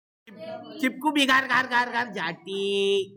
chipku biggar Meme Sound Effect
Category: Sports Soundboard